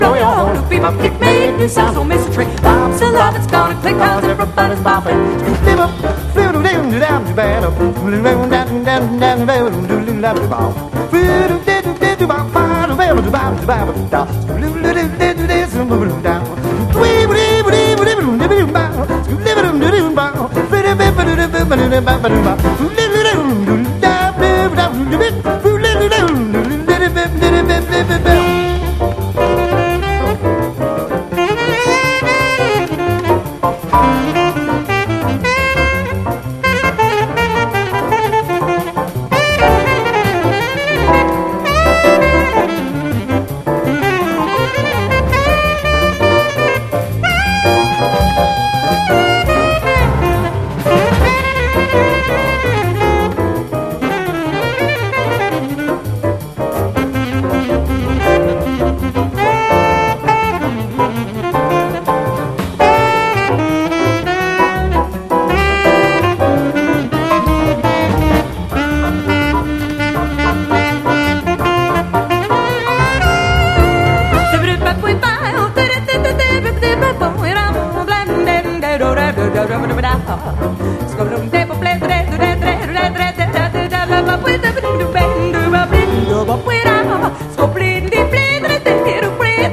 スウィンギンなギターが最高な高速ジャイヴ・コーラス